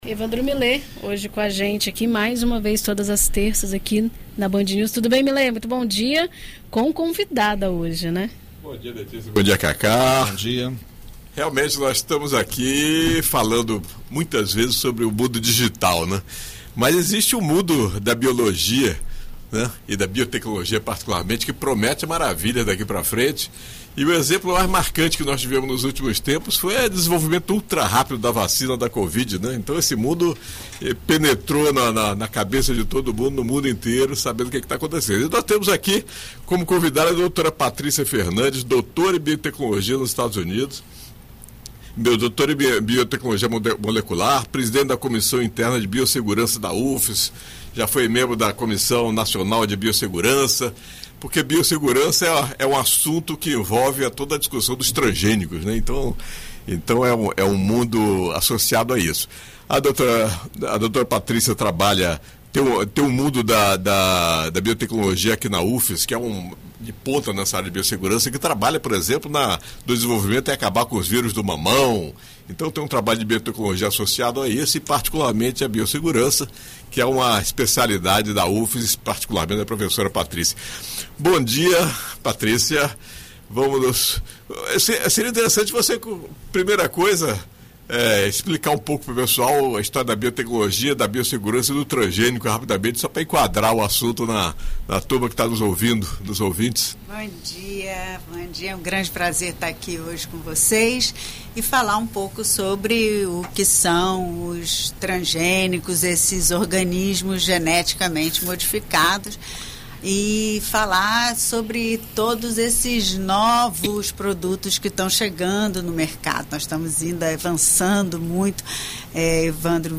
Em entrevista à BandNews FM Espírito Santo nesta terça-feira (25)